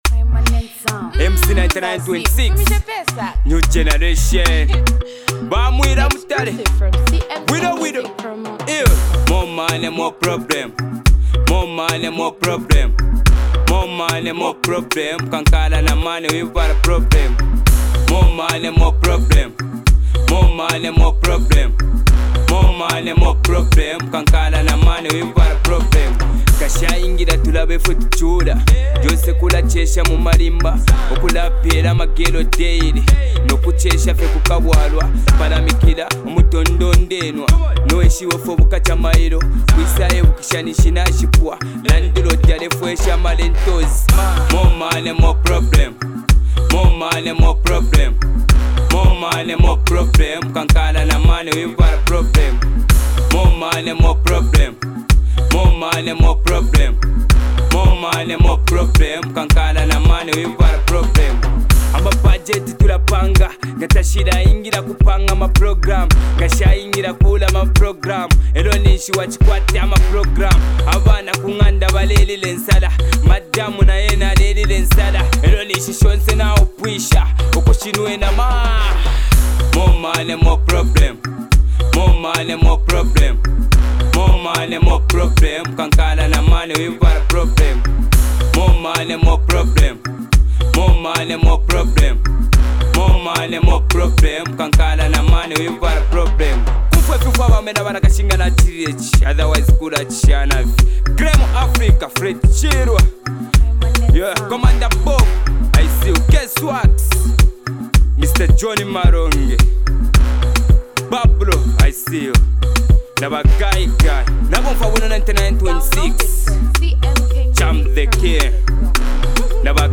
Catching vocals